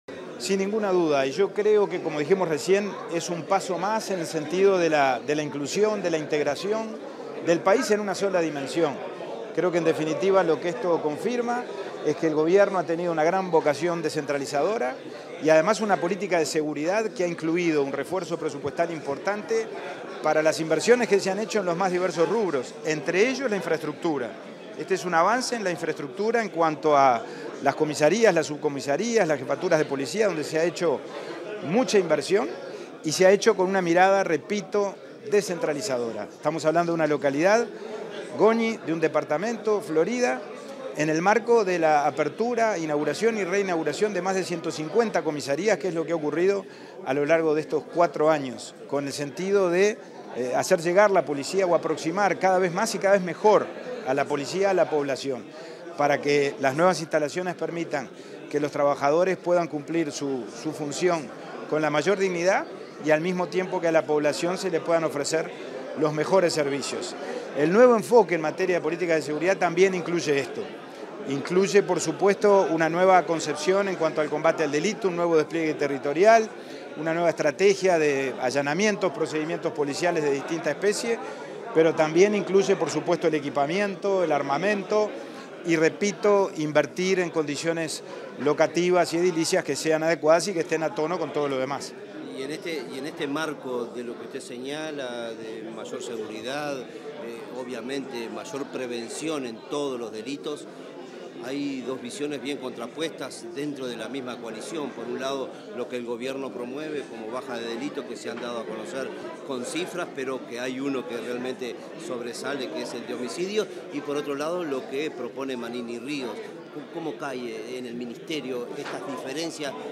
Declaraciones del subsecretario del Interior, Pablo Abdala 21/08/2024 Compartir Facebook X Copiar enlace WhatsApp LinkedIn Tras la inauguración del local de la seccional 13.ª en Goñi, este 21 de agosto, el subsecretario del Interior, Pablo Abdala, realizó declaraciones a la prensa.